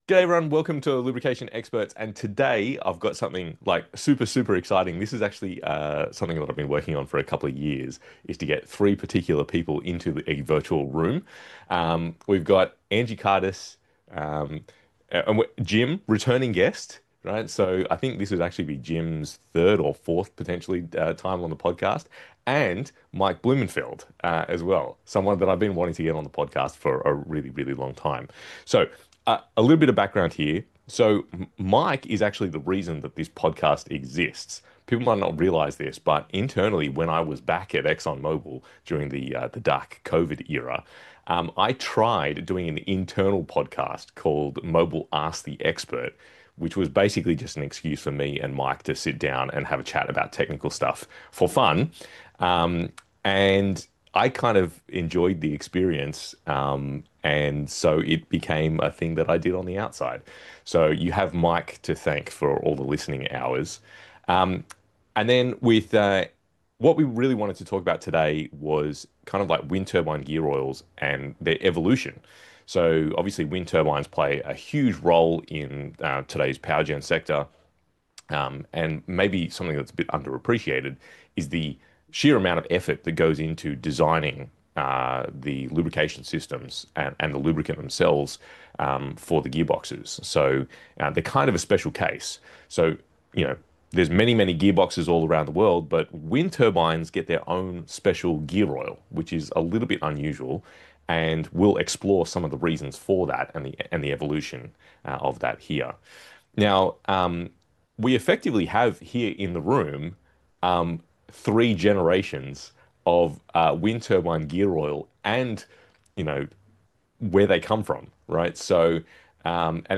In this in-depth conversation